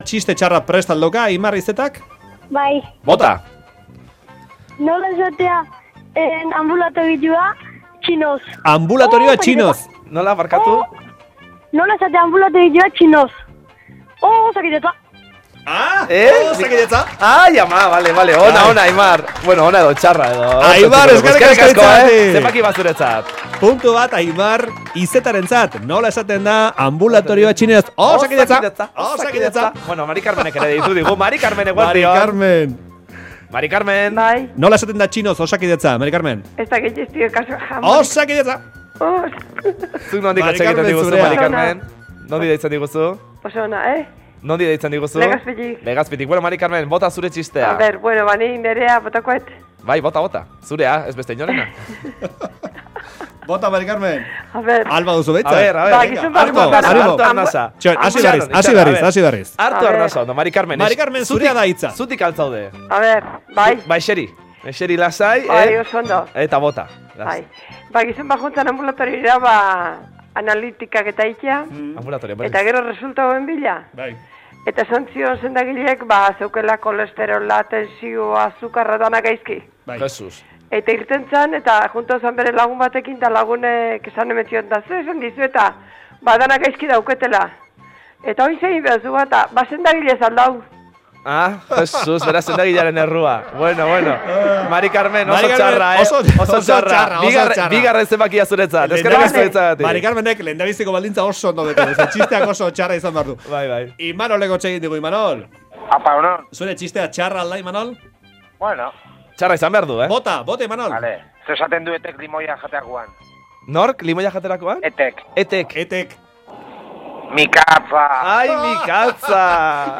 Entzuleek kontatu dizkiguten lehen txiste txarrak
Lehertu barrez entzuleek Txiste txarren lehiaketaren 1. egunean kontatu dizkiguten umorezko pasarteekin. Aste osoan zabalduko dugu zuzeneko telefonoa 13:45ean.